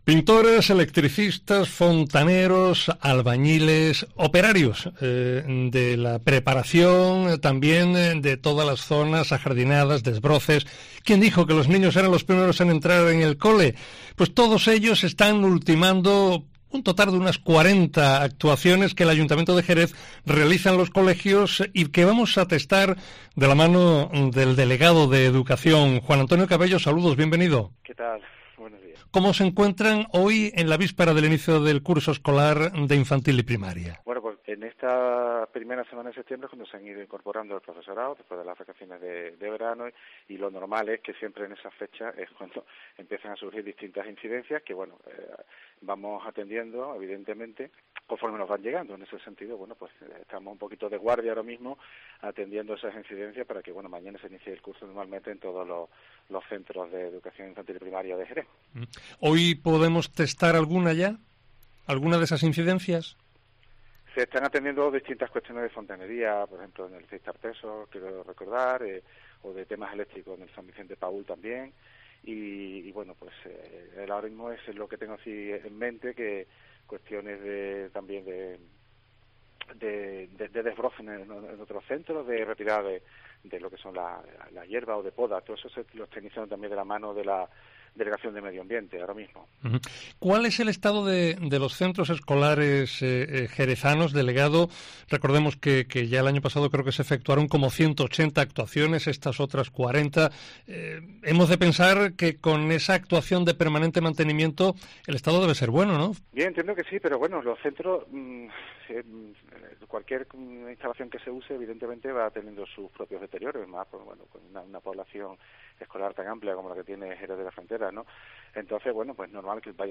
Juan Antonio Cabello, delegado de Educación del Ayuntamiento de Jerez
Juan Antonio Cabello, delegado de Educación del Consistorio, ha expresado su deseo de mantener una reunión con el delegado territorial para tratar asuntos como las aulas prefabricadas o la ratio alumno/profesorado. Puedes conocer todos los detalles de estos asuntos escuchando la entrevista completa.